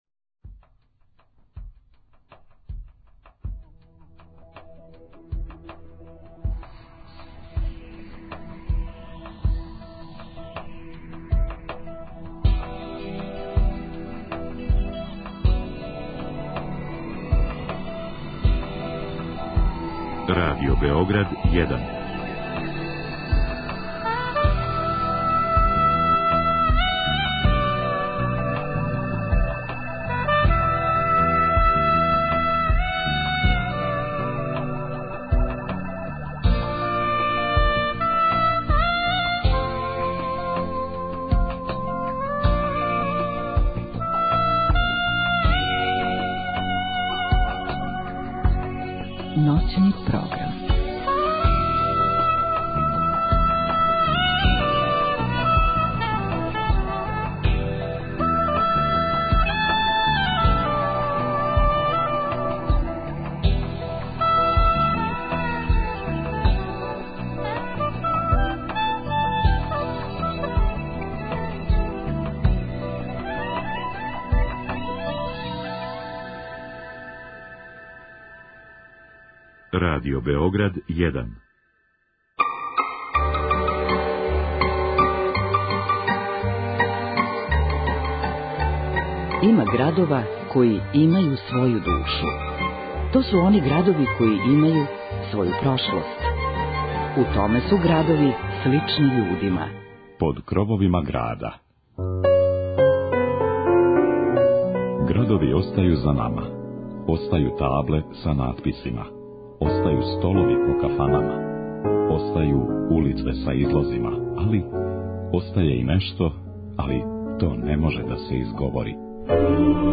Ове ноћи причом и песмом прошетаћемо подунавским округом. Прецизније, бићемо под крововима Смедеревске Паланке, која са седамнаест села у свом округу има богату историју.